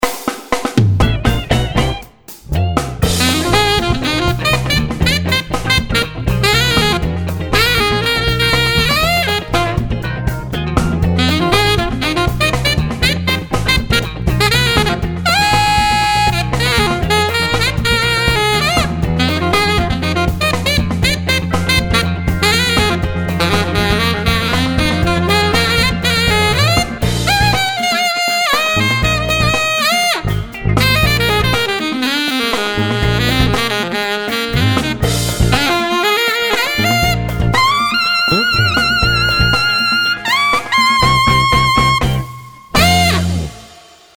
聞き比べ アルト
HLAltoFunk.mp3